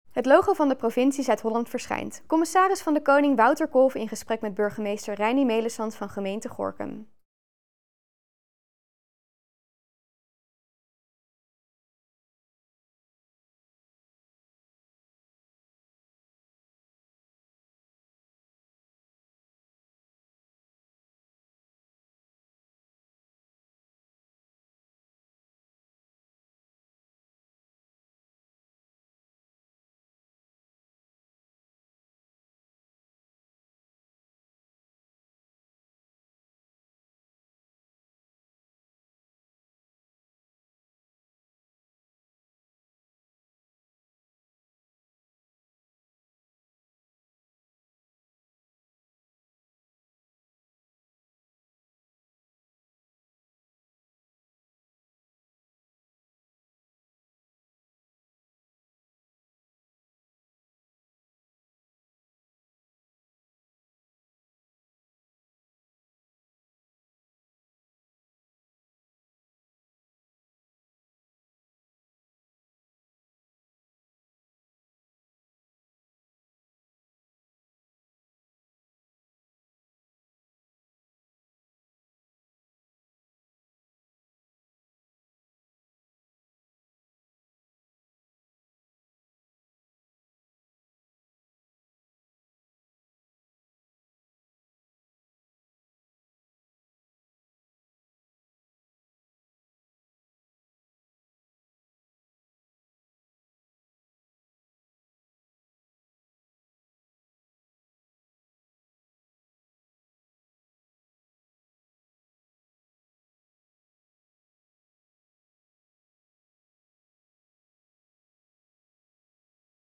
Commissaris van de Koning Wouter Kolff in gesprek met de burgemeester van Gorinchem.